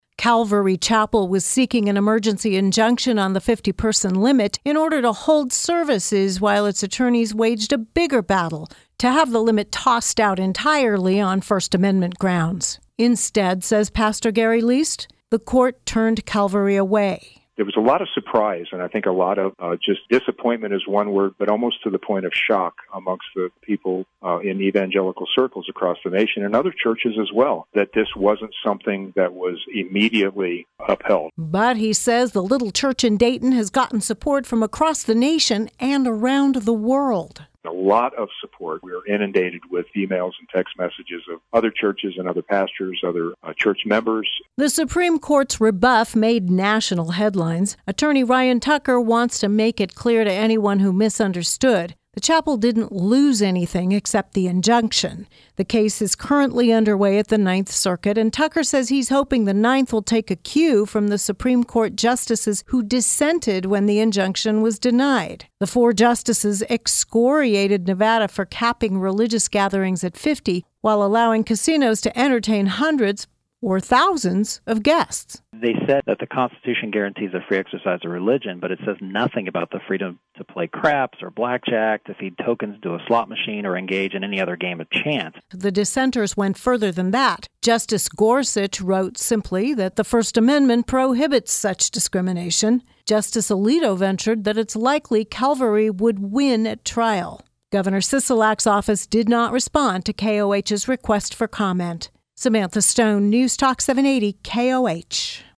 continues our news series on the Calvary Chapel of Dayton versus Nevada.